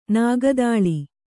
♪ nāga dāḷi